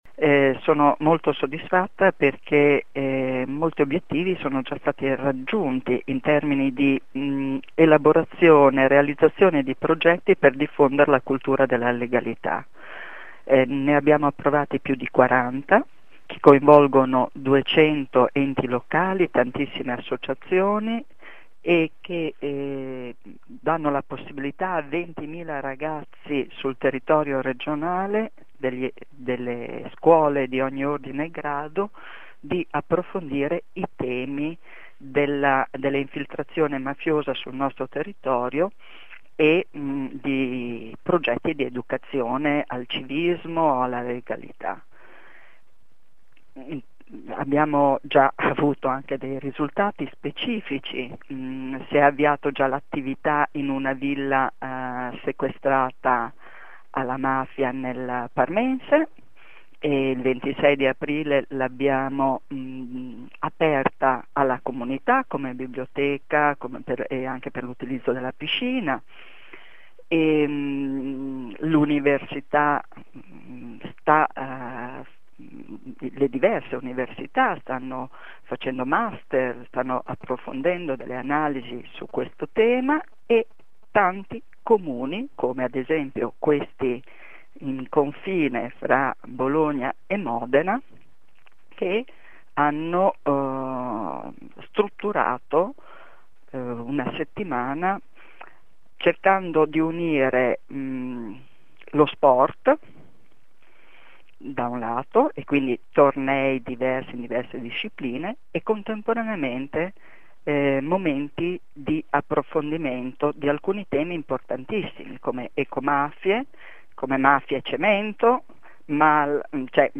Il festival sarà l’occasione per raccogliere i frutti dell’applicazione della legge regionale antimafia, a un anno dall’approvazione. Ascolta la vicepresidente Simonetta Saliera